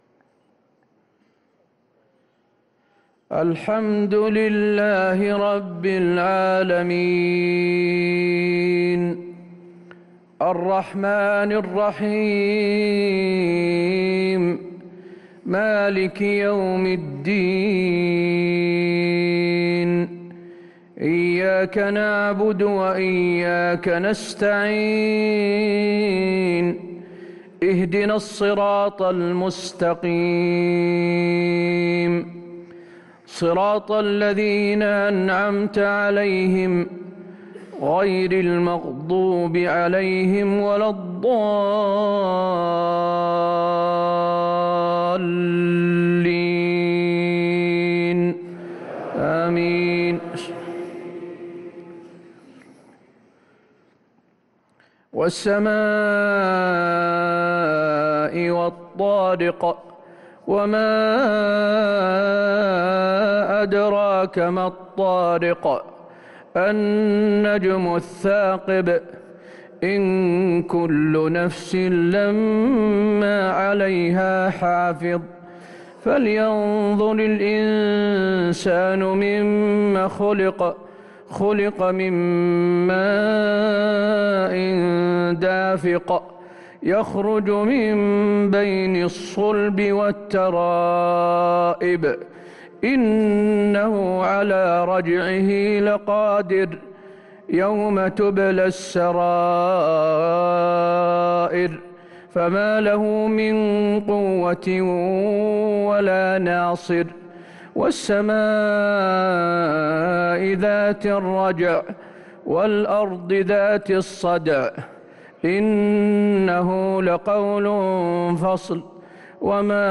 صلاة العشاء للقارئ حسين آل الشيخ 25 رمضان 1444 هـ